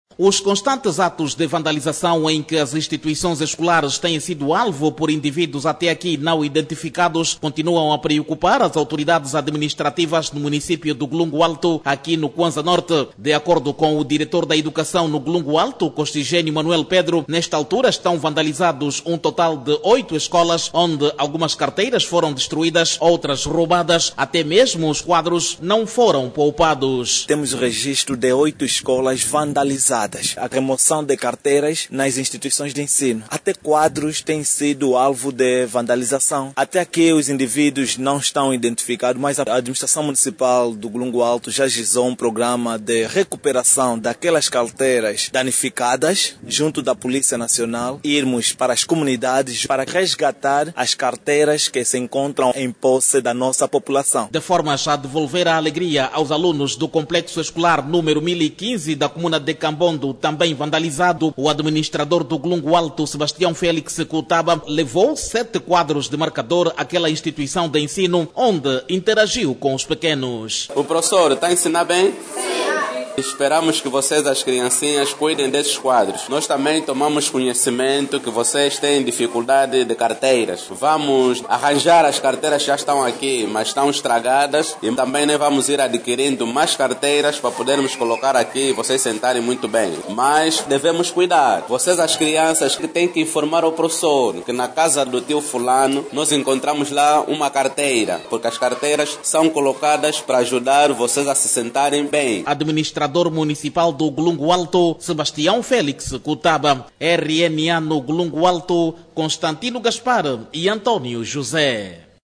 No município do Golungo Alto, oito escolas foram recentemente vandalizadas. Os autores dos crimes levaram carteiras, quadros e outros bens essenciais ao funcionamento das instituições de ensino. Saiba mais dados no áudio abaixo com o repórter